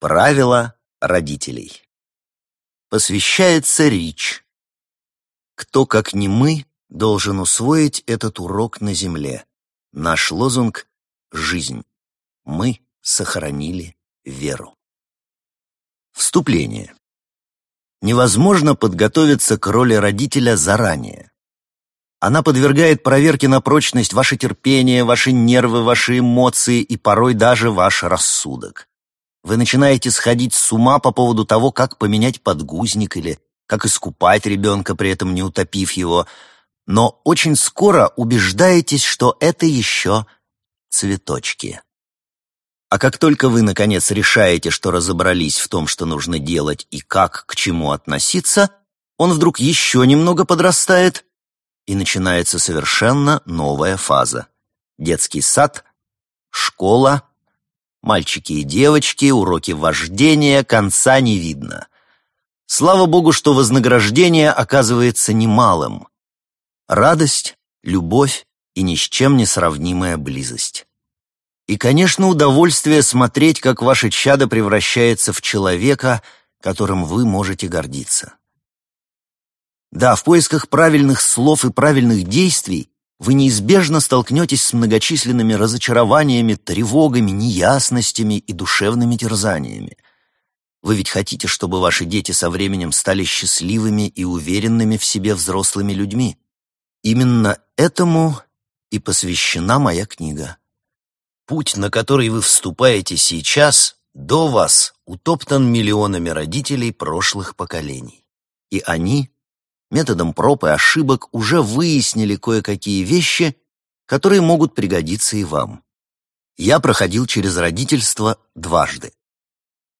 Аудиокнига Правила родителей - купить, скачать и слушать онлайн | КнигоПоиск